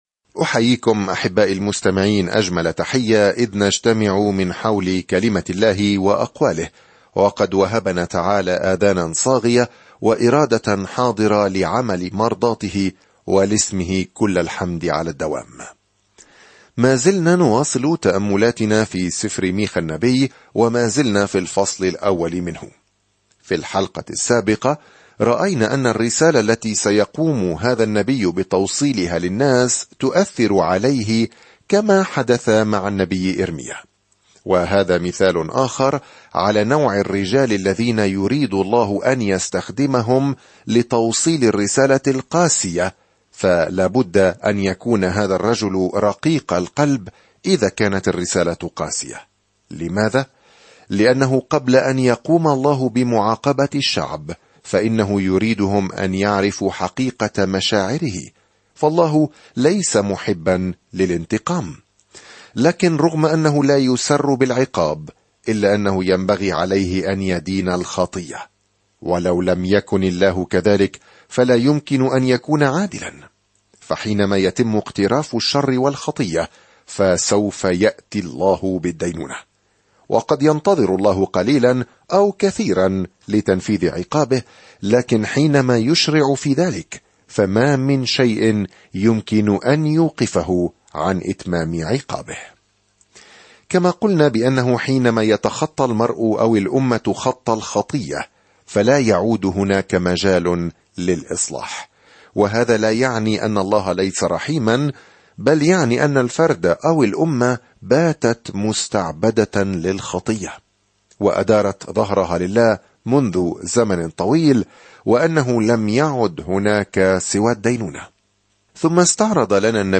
الكلمة مِيخَا 13:1-16 مِيخَا 1:2-5 يوم 3 ابدأ هذه الخطة يوم 5 عن هذه الخطة في نثر جميل، يدعو ميخا قادة إسرائيل ويهوذا إلى محبة الرحمة، والتصرف بالعدل، والسير بتواضع مع الله. سافر يوميًا عبر ميخا وأنت تستمع إلى الدراسة الصوتية وتقرأ آيات مختارة من كلمة الله.